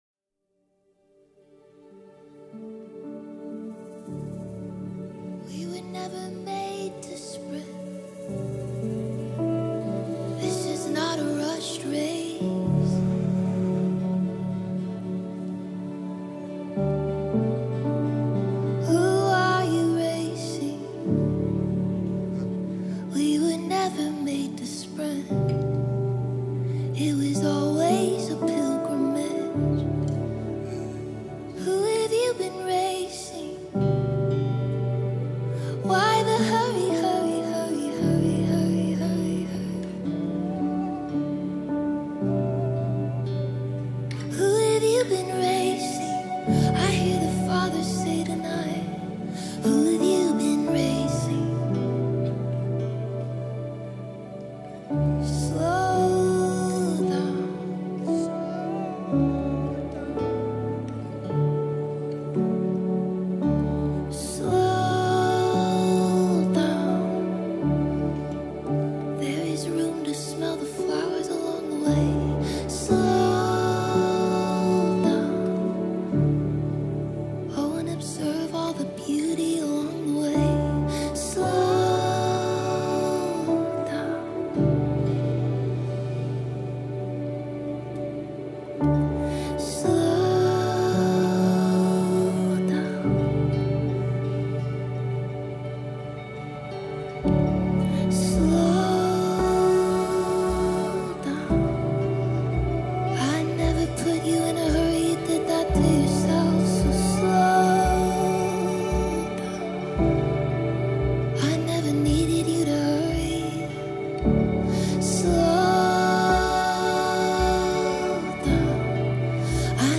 94 просмотра 124 прослушивания 8 скачиваний BPM: 85